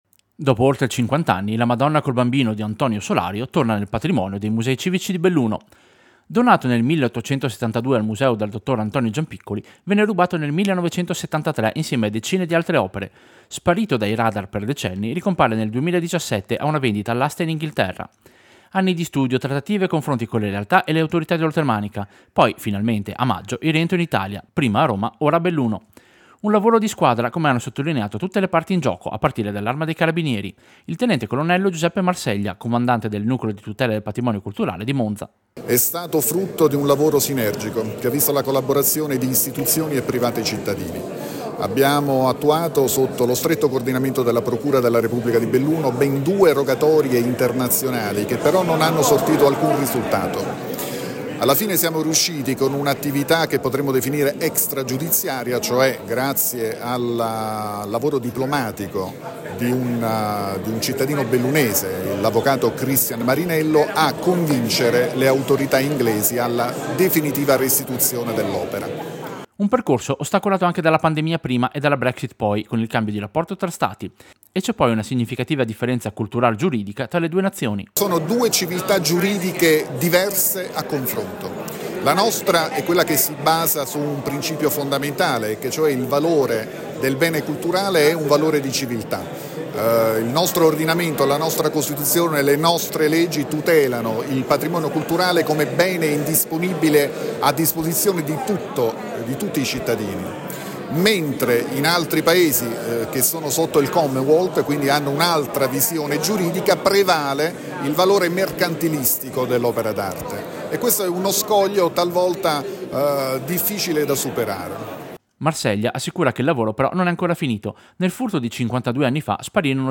Servizio-Quadro-rubato-restituito.mp3